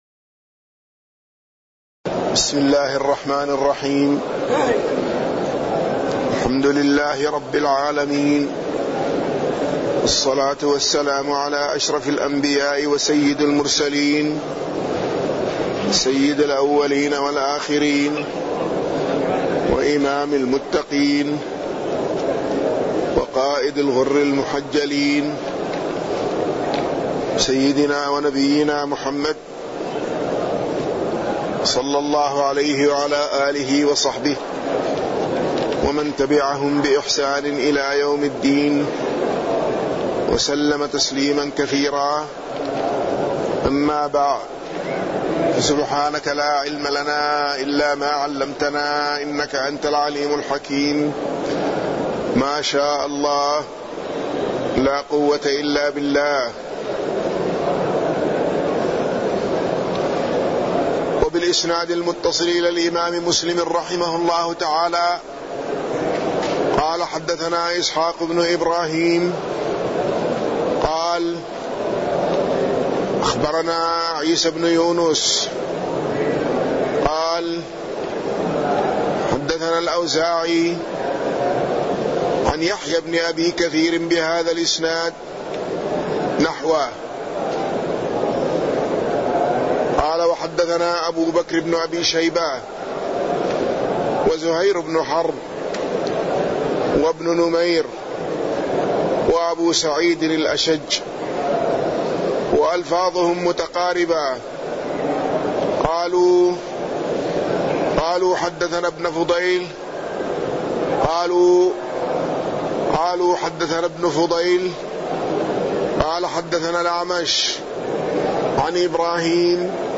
تاريخ النشر ١٣ جمادى الأولى ١٤٢٩ هـ المكان: المسجد النبوي الشيخ